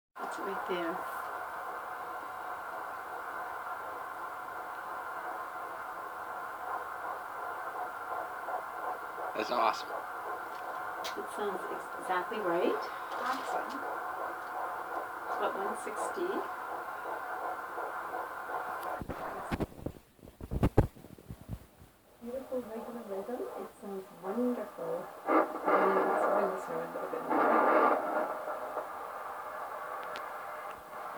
Baby’s heartbeat
Babys-heartbeat.mp3